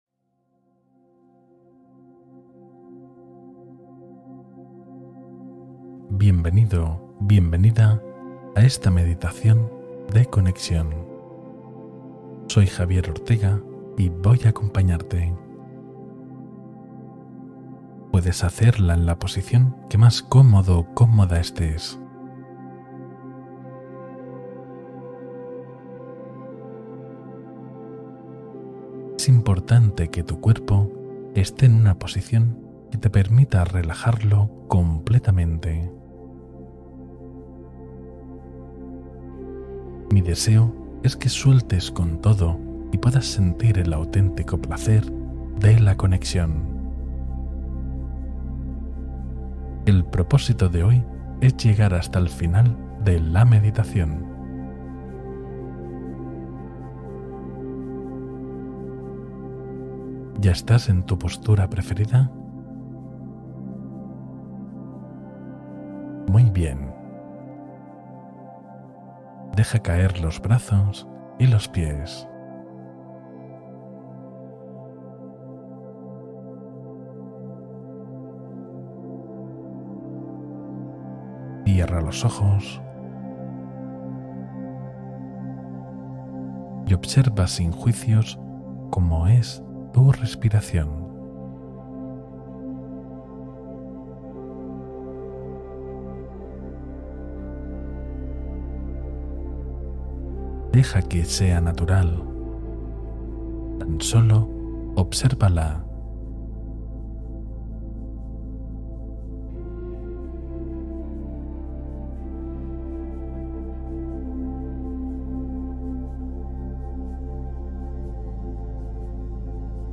Exploración Astral Simbólica: Meditación de Expansión de la Conciencia